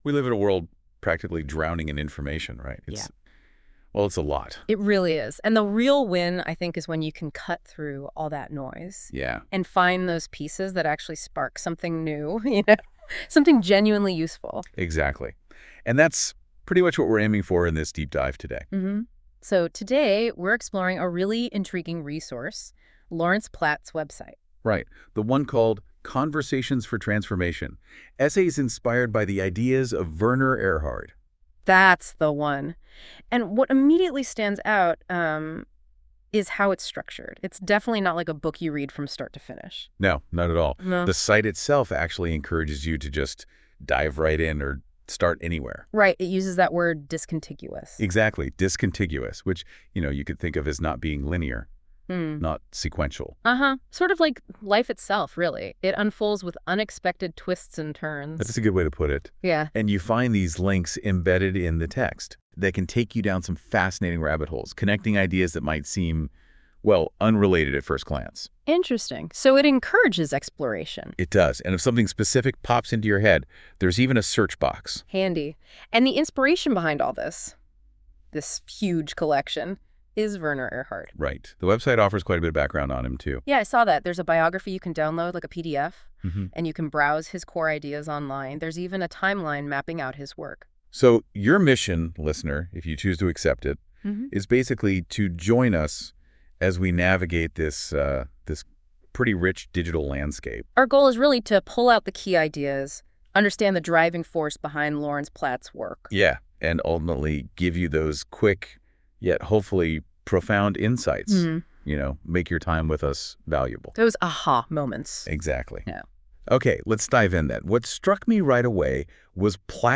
One last thing: there's a subtle yet common error in the podcast for which I would like to make a correction. It's how it pronounces Werner's name.